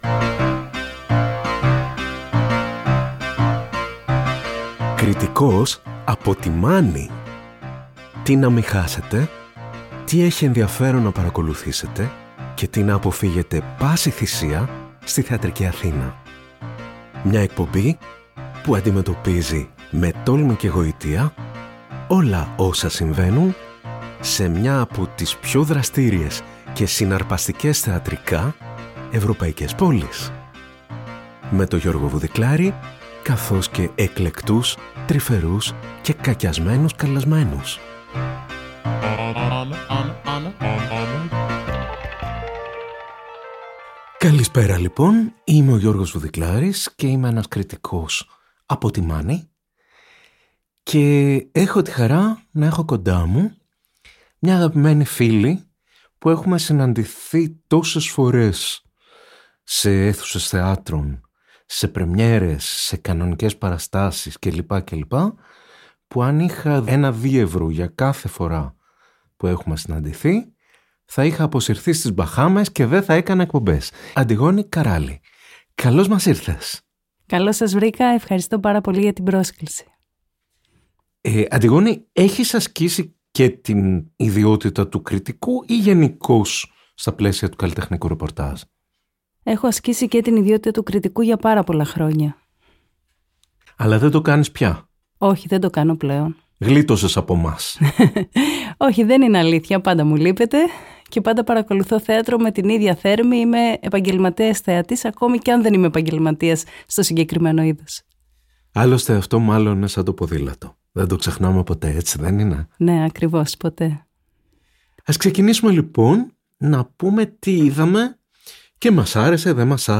Μια νέα εκπομπή που θα μάς συστήνει όλες τις θεατρικές παραστάσεις που αξίζει να γνωρίζουμε για τους σωστούς ή τους… λάθος λόγους! Συζητήσεις μεταξύ κριτικών που συμφωνούν ή διαφωνούν για το τι δεν πρέπει να χάσουμε, αλλά και το τι πρέπει να αποφύγουμε στη θεατρική Αθήνα.